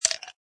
ice3.mp3